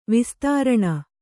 ♪ vistāraṇa